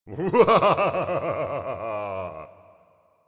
Laugh_Evil_02
epic fantasy fear frightening frightful game gamedev gamedeveloping sound effect free sound royalty free Funny